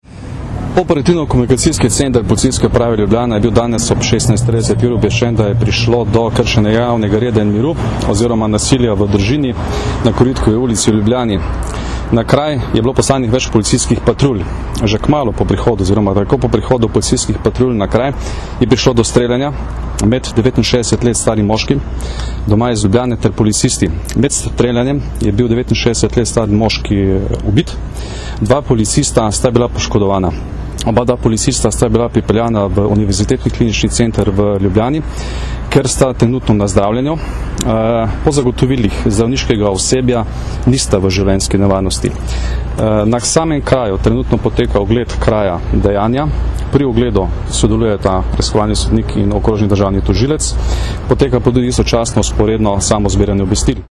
Zvočni posnetek izjave generalnega direktorja policije Janka Gorška (mp3)